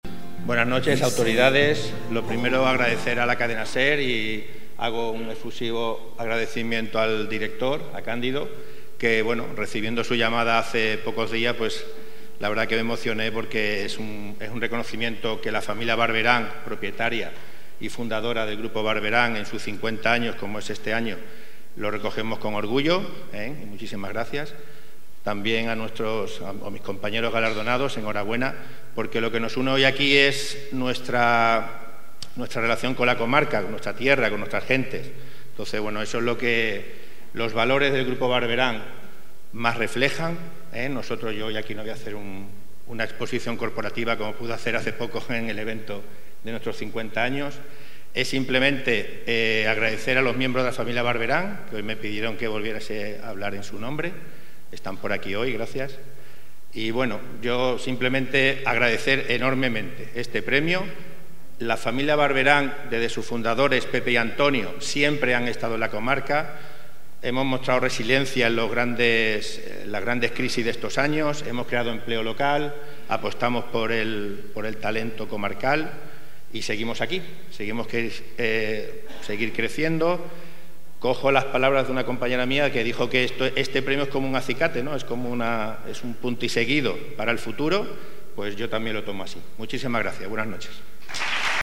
En alcalde de San Roque, Juan Carlos Ruiz Boix, así como la primera teniente de alcalde María del Mar Collado, el teniente de alcalde David Ramos, y otros ediles de la Corporación, han asistido al acto de entrega de galardones, que ha tenido lugar en el Casino Admiral de San Roque.